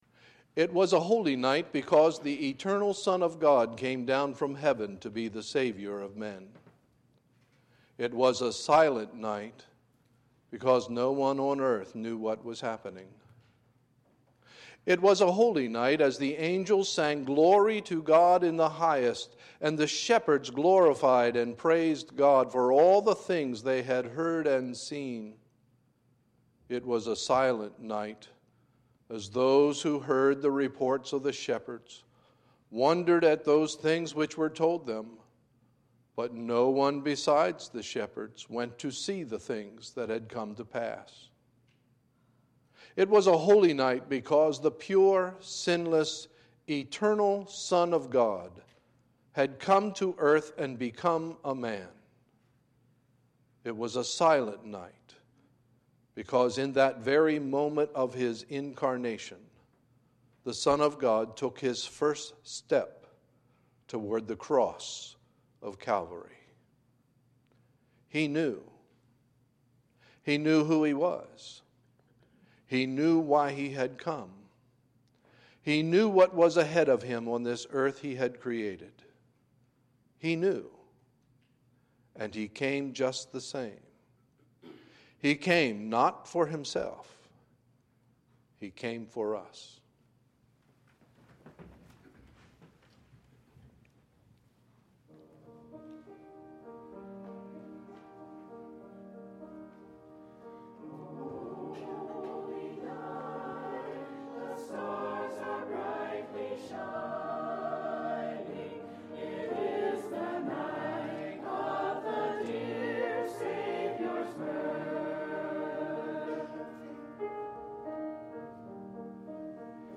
Sunday, December 17, 2017 – Adult Christmas Music Program